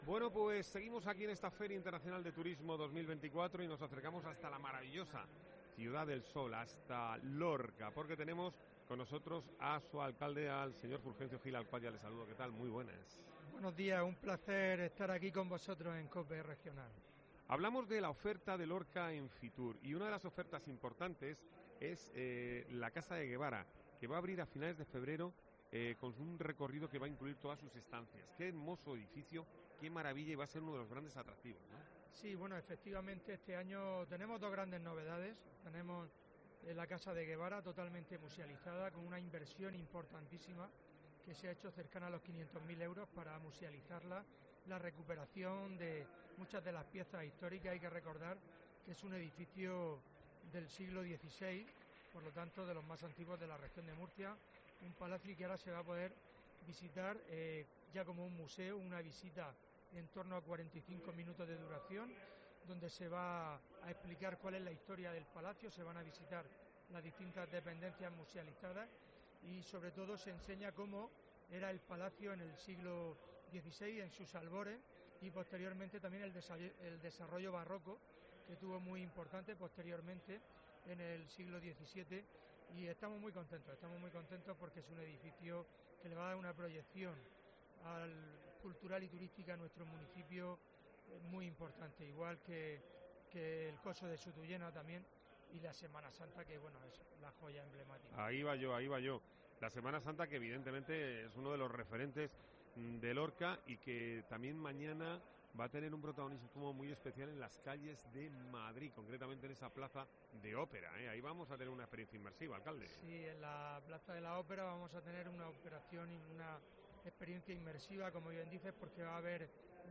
Fulgencio Gil, alcalde de Lorca en FITUR 2024